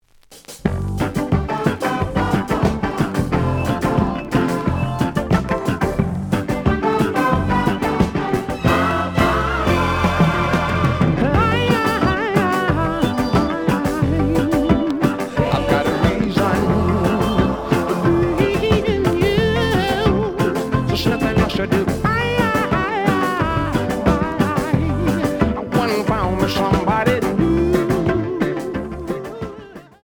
(Stereo)
試聴は実際のレコードから録音しています。
●Genre: Funk, 70's Funk